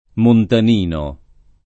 montan&no] (ant. montagnino [montan’n’&no]) agg. e s. m. — sim. i top. Montanino e la Montanina (Tosc.), e i cogn. Montagnin [montan’n’&n], Montagnini, -no, Montanini — cfr. adamantino